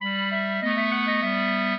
clarinet
minuet9-9.wav